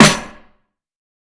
TC3Snare19.wav